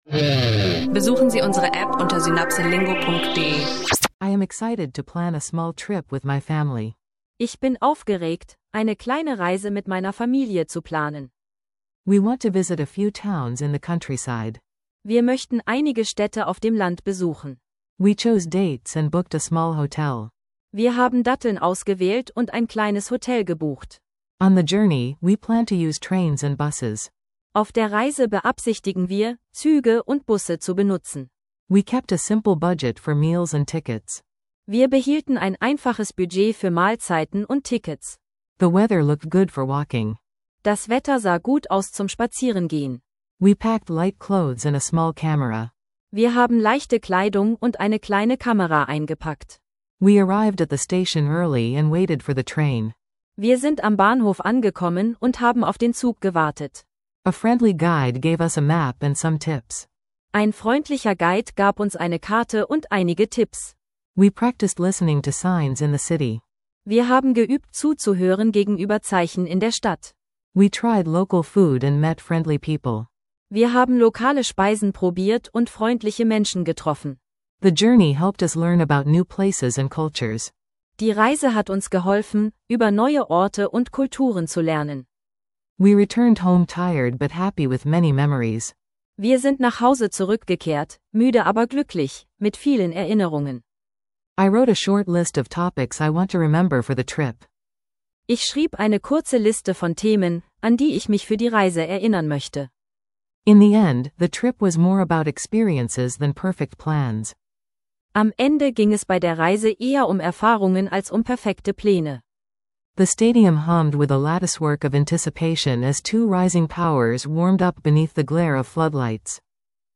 Zwei praxisnahe Geschichten: Travel-Pläne und U20-Fußball – Englisch lernen durch realistische Dialoge und Alltagssprache